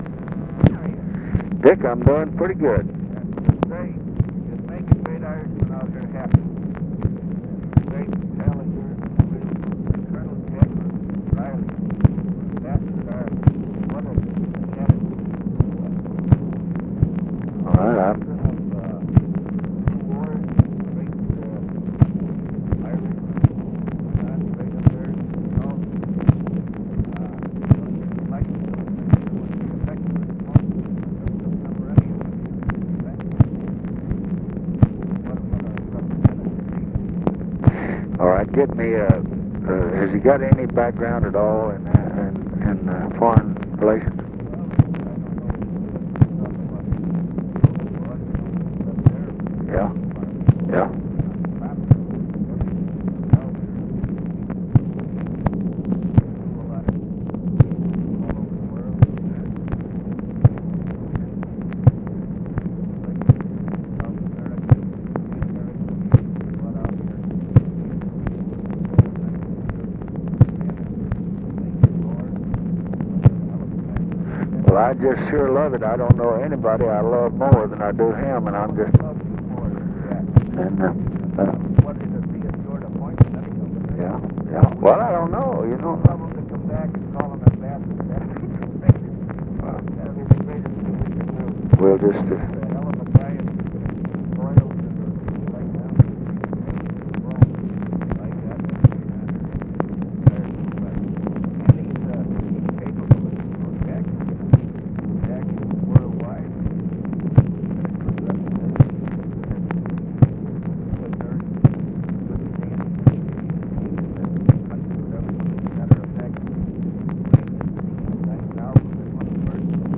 Conversation with RICHARD DALEY, July 13, 1968
Secret White House Tapes